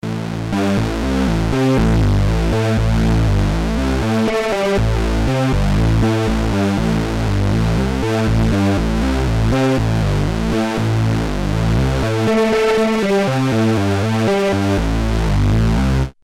RS6 – MEAN BASSLEAD | Supercritical Synthesizers
RS6-MEAN-BASSLEAD.mp3